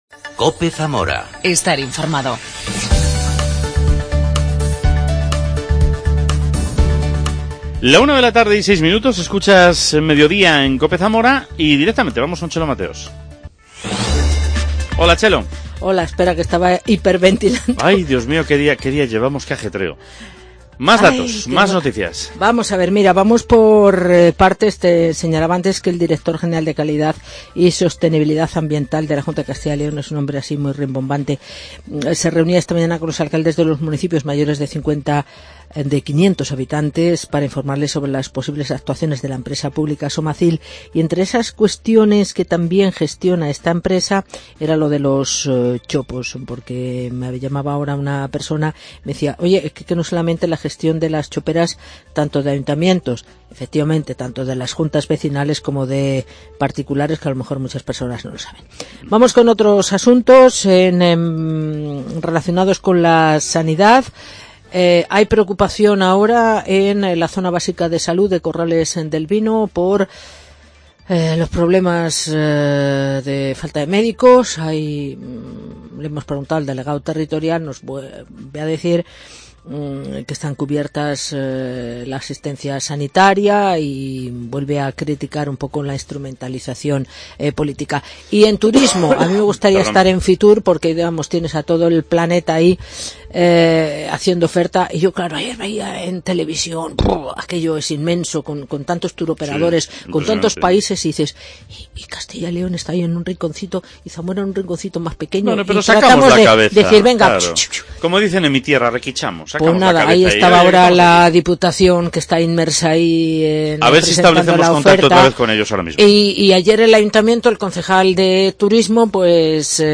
AUDIO: La Presidenta de la Diputación de Zamora, Mayte Martín Pozo habla de Fitur.